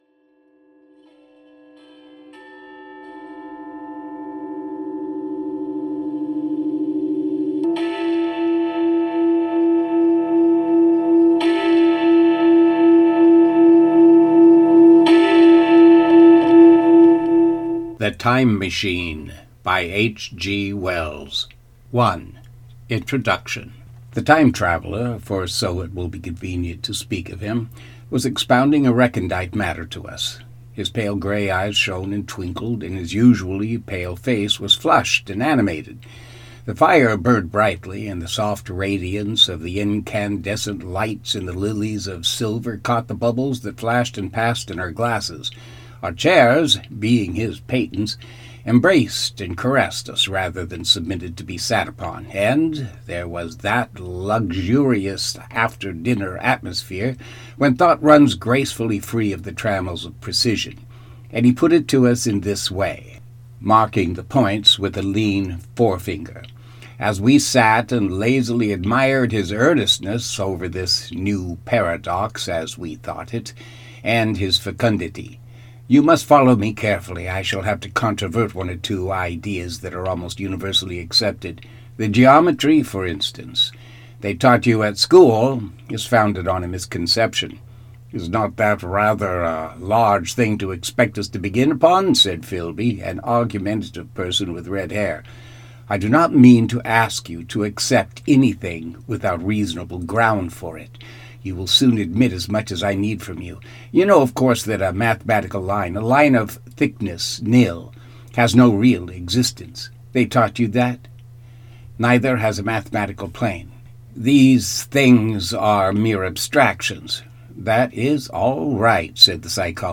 The Time Machine – HG Wells – Audio Book